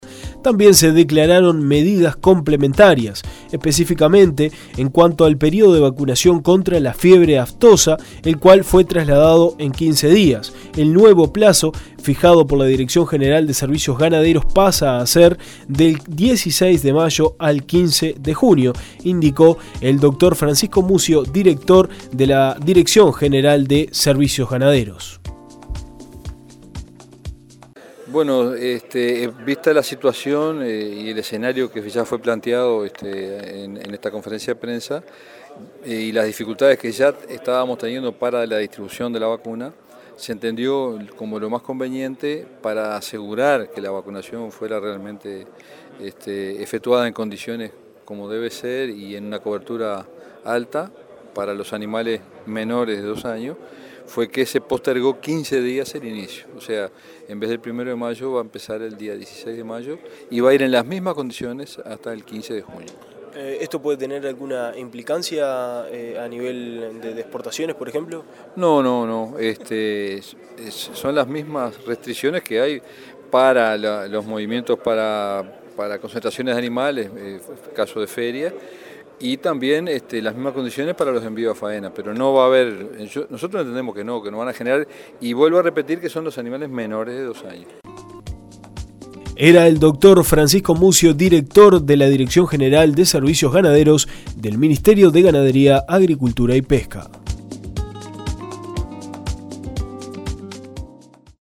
La medida abarca a productores ganaderos que están inmersos en las zonas afectadas. En el área radican 320 productores familiares que cuentan con un rodeo de alrededor de 34.000 vacunos, por lo que hasta el momento 120 de ellos ya solicitaron la ración correspondiente, el plazo para la inscripción vence el 29 de abril informó el Ministerio de Ganadería este miércoles en conferencia de prensa.